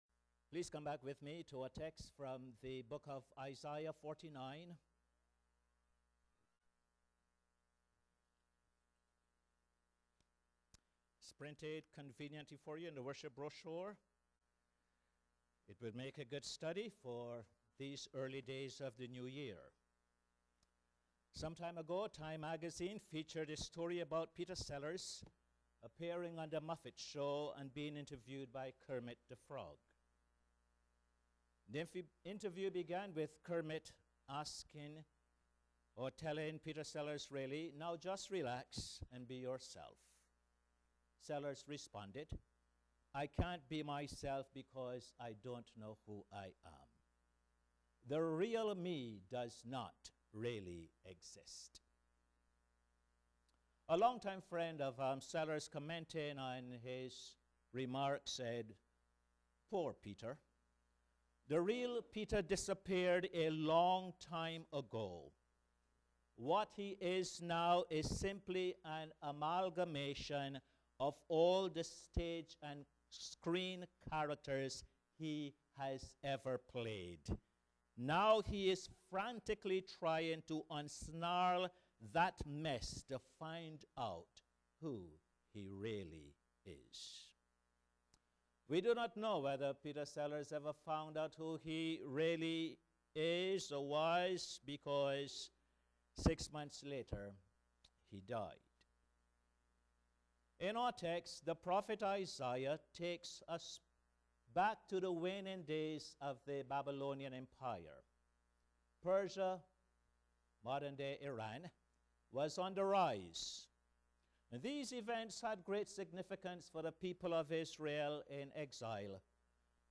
Posted in Sermons on 10.